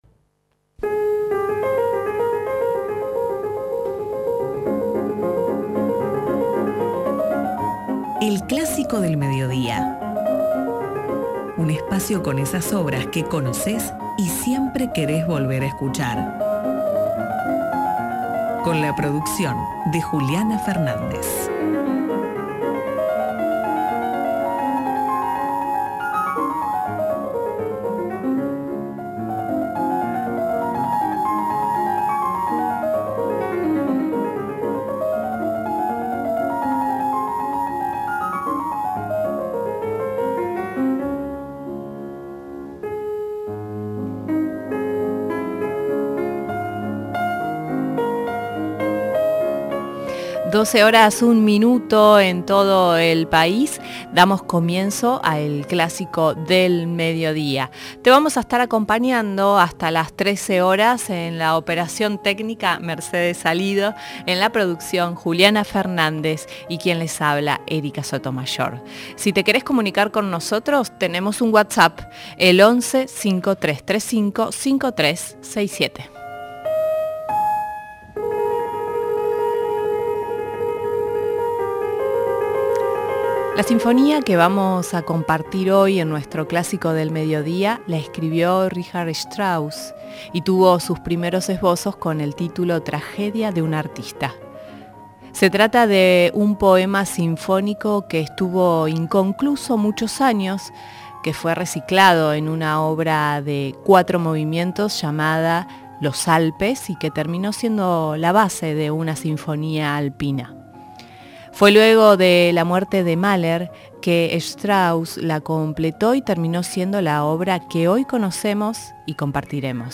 Esta vez paseamos todo el día, desde el alba hasta el anochecer, de la mano de Richard Strauss y su Sinfonía Alpina, op.64. La descripción de este viaje, estará a cargo de la Orquesta del Concertgebouw de Ámsterdam, bajo la dirección de Bernard Haitink.